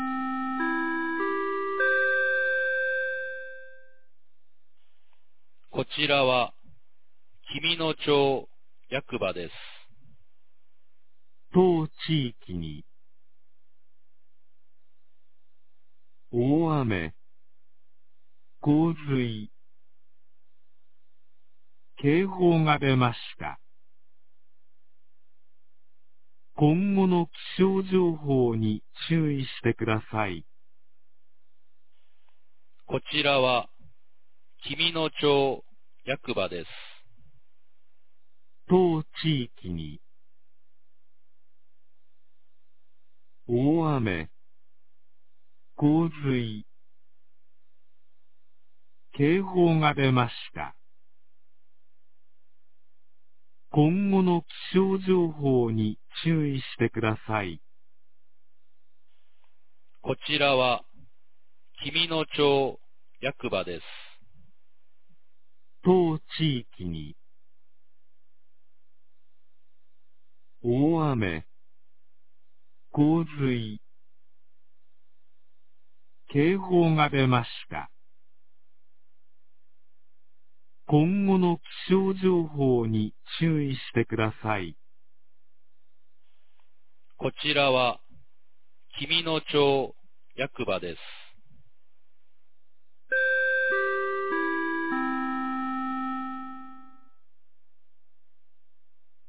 2023年06月02日 09時11分に、紀美野町より全地区へ放送がありました。
放送音声